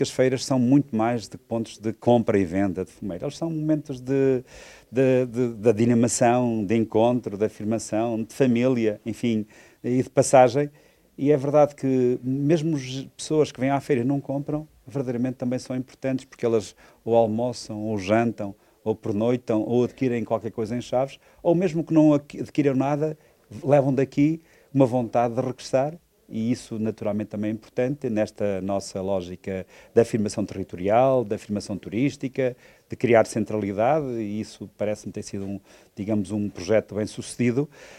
Para o autarca o evento vai muito além da venda de produtos, assume-se como um espaço de identidade, encontro e afirmação do território: